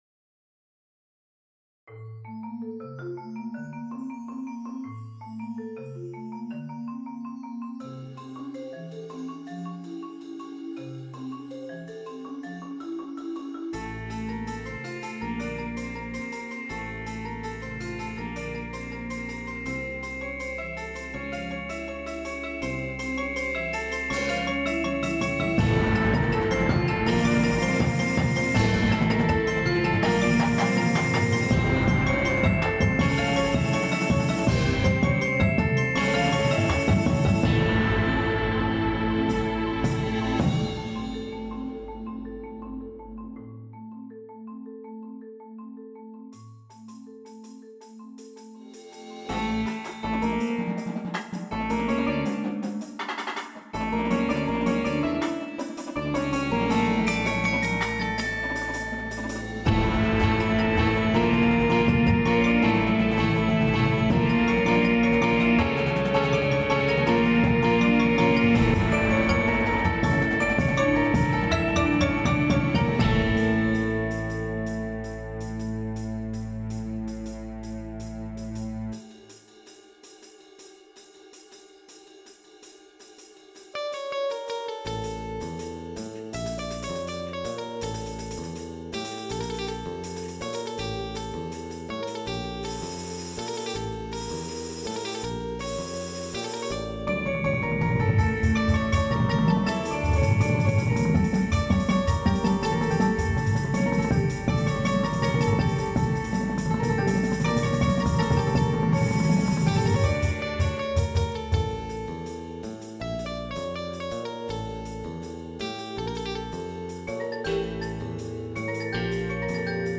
Mysterious, driving, and exotic.
Snares
Tenors
5 Bass Drums
Glockenspiel
Xylophone
Marimba 1, 2
Vibraphone
Electric Guitar
Bass Guitar
Synth 1, 2
Auxiliary Percussion 1, 2, 3